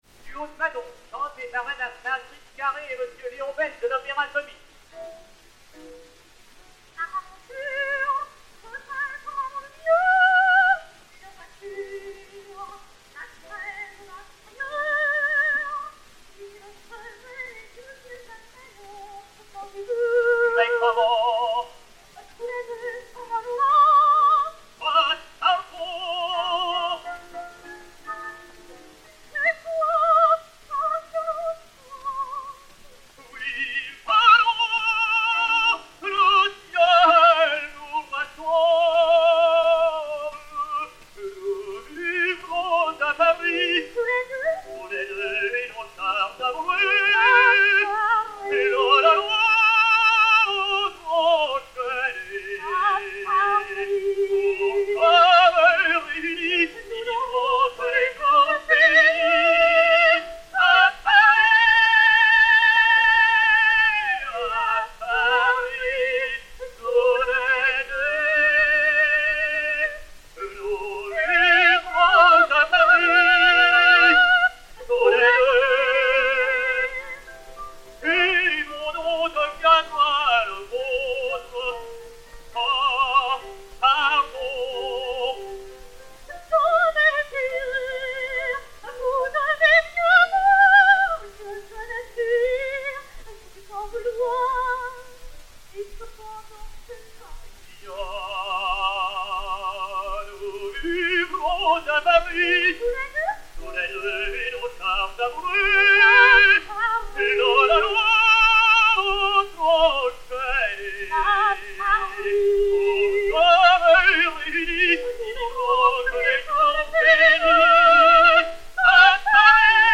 Marguerite Carré (Manon), Léon Beyle (Des Grieux) et Piano